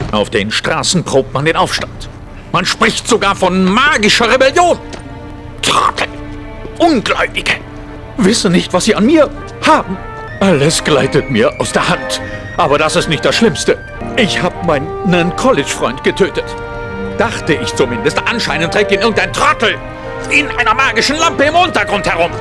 WARNUNG: Die deutsche Fassung ist unverhältnismäßig laut eingebunden worden, entsprechend sind auch die Samples teilweise recht laut.
Die Übersteuerungen sind echt ziemlich krass, da hätte man wirklich bei der Abmischung drauf achten können.